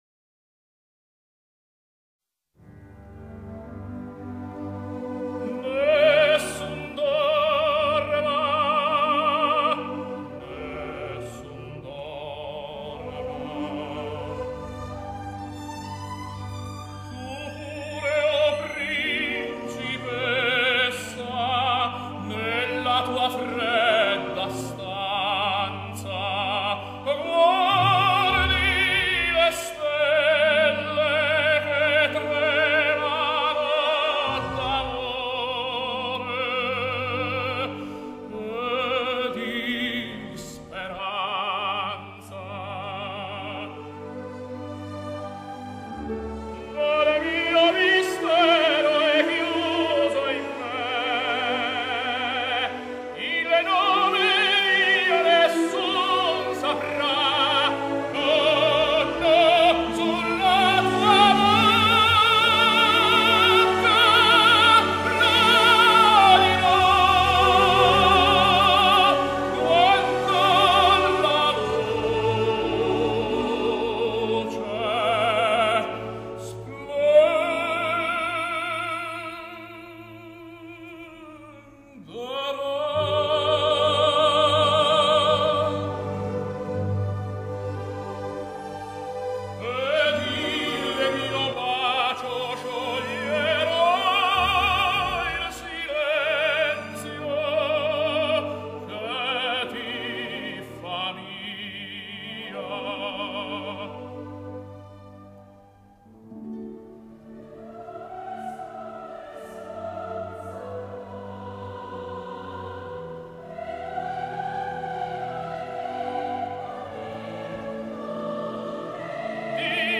Genre: Opera, Vocal, Classical